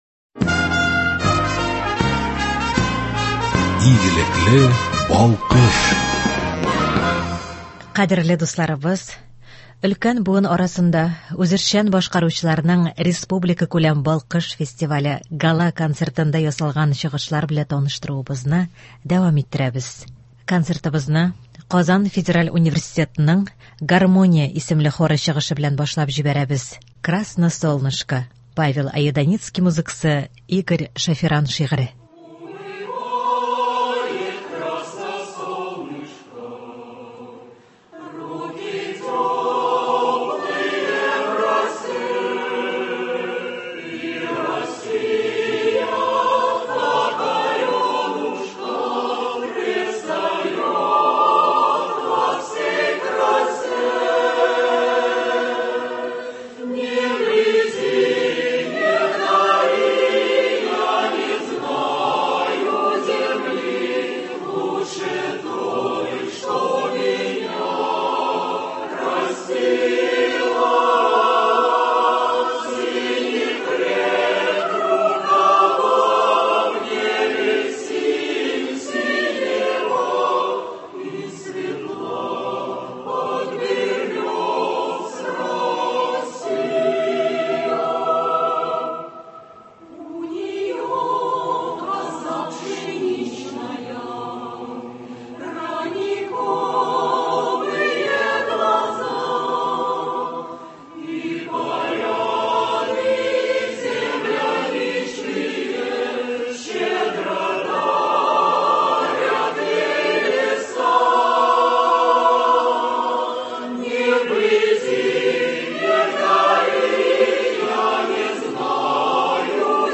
Кадерле дусларыбыз, өлкән буын арасында үзешчән башкаручыларның республикакүләм «Балкыш» фестивале Гала-концертында ясалган чыгышлар белән таныштыруыбызны дәвам иттерәбез.